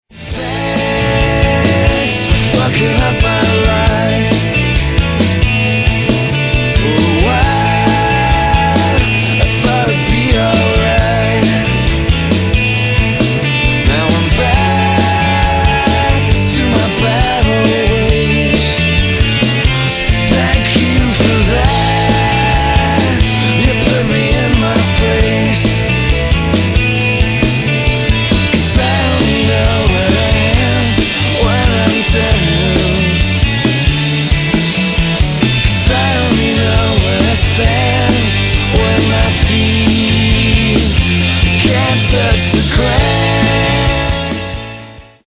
Vocals, Acoustic Guitar
Bass, Vocals, Accordion, Harmonium
Electric Guitar, Vocals, Pedal Steel
Drums, Vocals, Percussion, Tack Piano